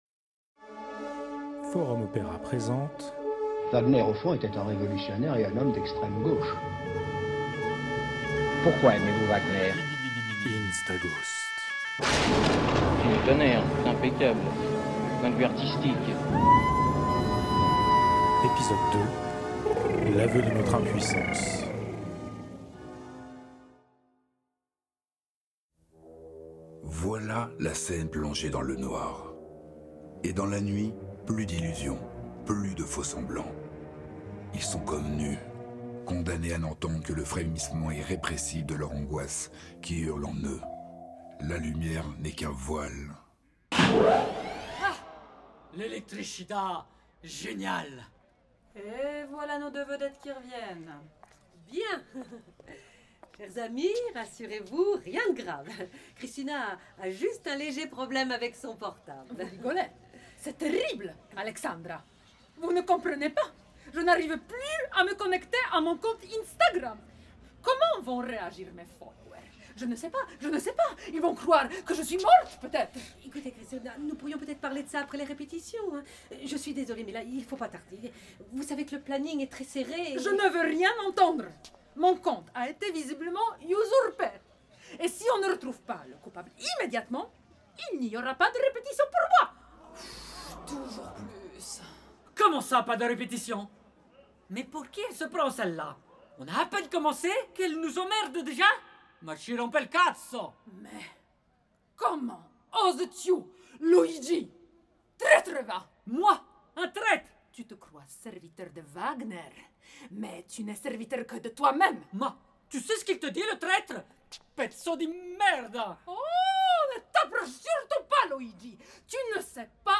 Instaghost – divertissement radiophonique, épisode 2 : L'aveu de notre impuissance - Forum Opéra
Où l’on entend avec stupeur ce qui peut surgir d’une alarme incendie Après le piratage du compte Instagram de Kristina, la production tourne à la cacophonie. Pendant ce temps, Alphonse rencontre les amis militants de Brune qui semblent préparer un gros coup…
Merci à MAB/Colligence Records pour le prêt du studio d’enregistrement.